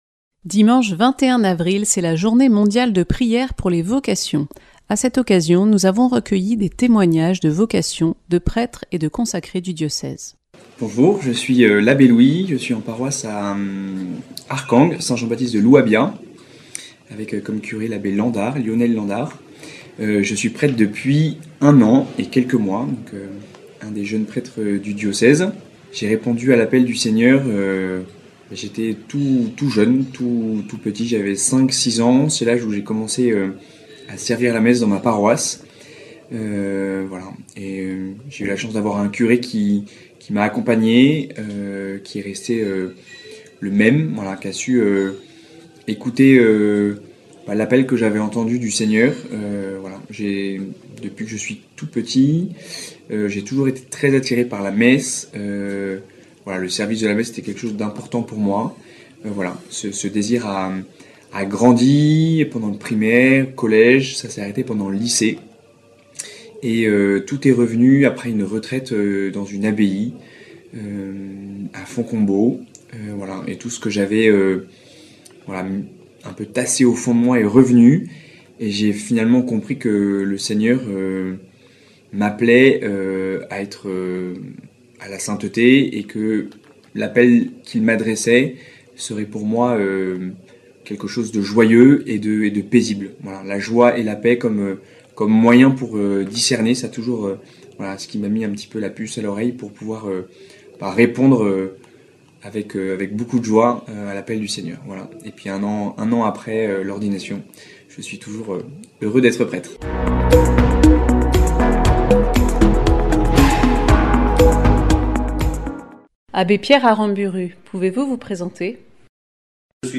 Témoignages de prêtres, de religieux et religieuses du diocèse.